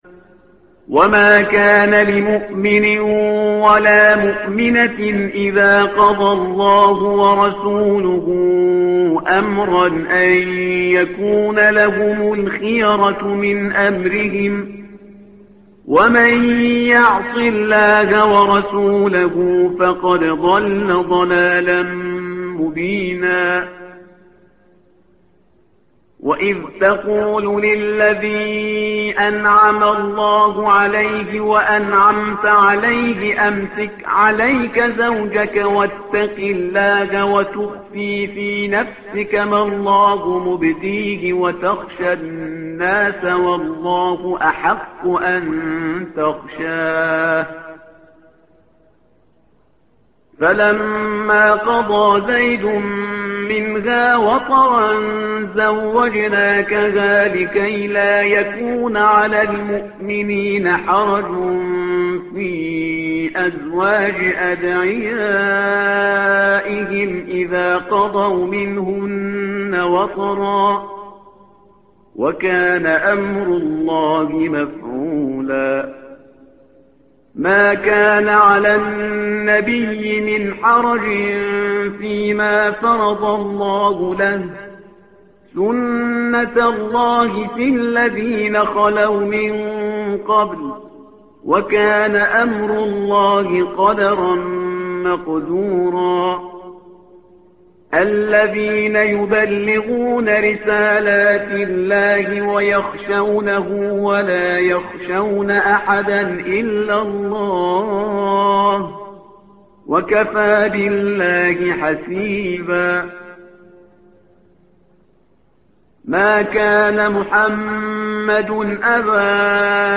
تحميل : الصفحة رقم 423 / القارئ شهريار برهيزكار / القرآن الكريم / موقع يا حسين